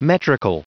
Prononciation du mot metrical en anglais (fichier audio)
Prononciation du mot : metrical